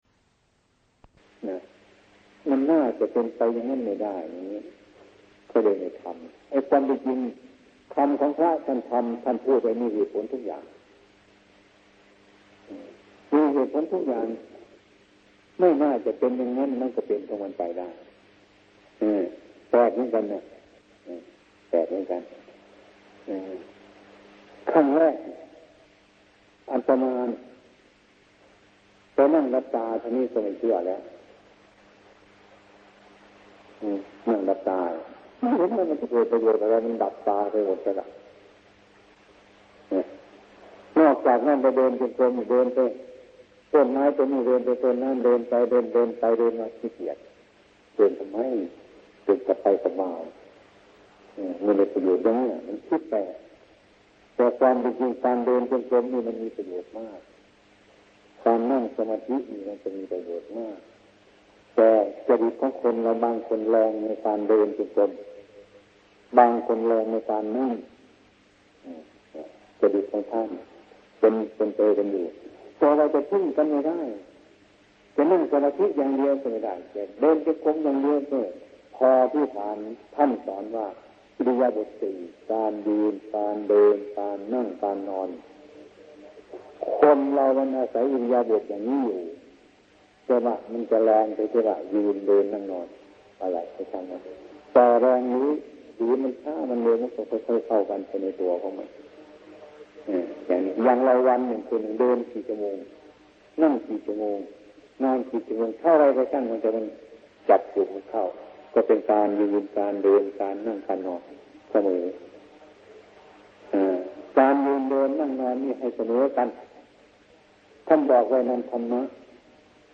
ธรรมเทศนาสำเนียงอิสาน ตอนที่ ๑๓-๓ ,หลวงปู่ชา สุภทฺโท